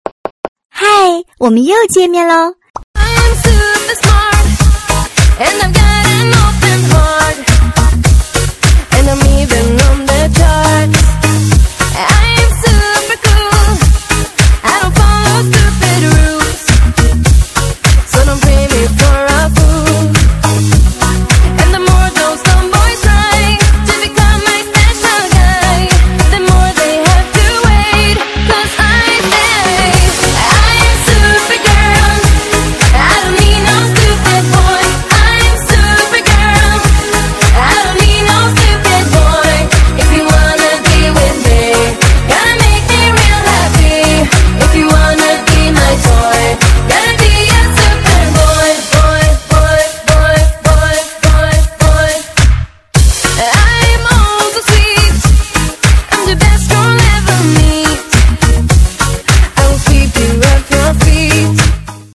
分类: DJ铃声